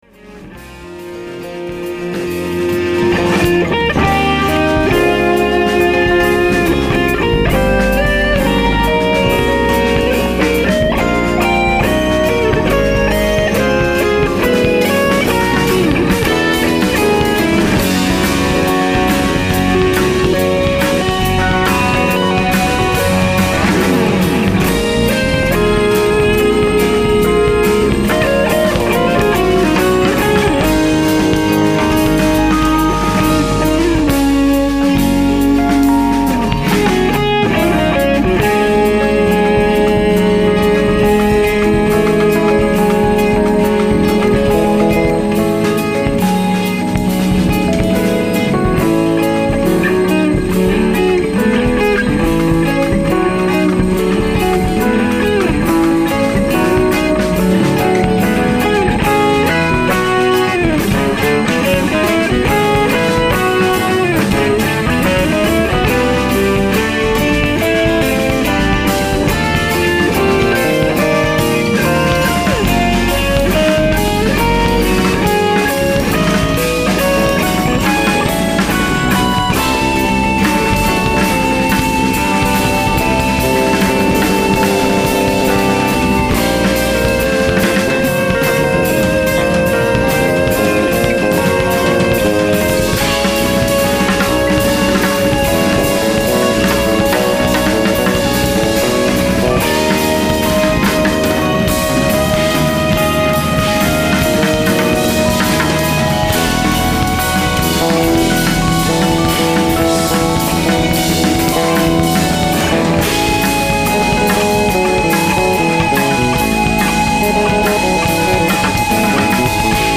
Electric and Acoustic Guitars
Keyboards
Electric and Acoustic Bass
Drums